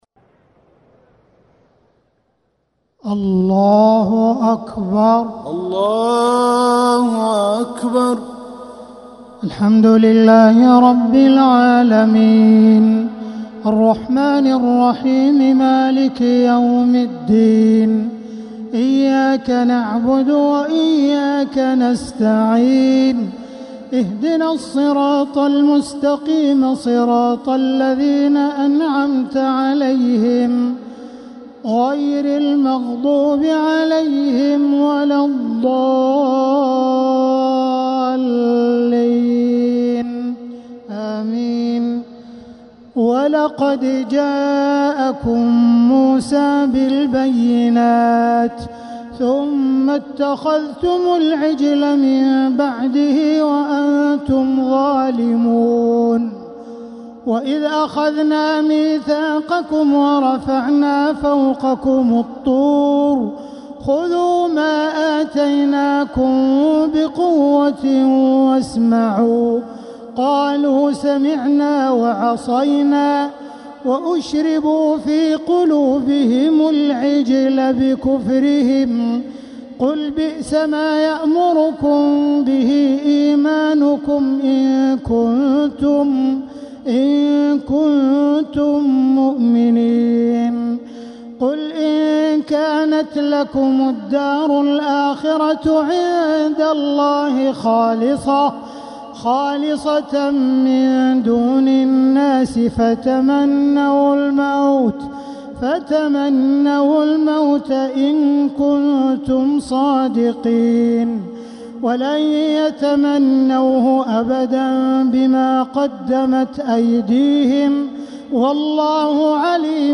تراويح ليلة 1 رمضان 1447هـ من سورة البقرة (92-105) Taraweeh 1st night Ramadan 1447H > تراويح الحرم المكي عام 1447 🕋 > التراويح - تلاوات الحرمين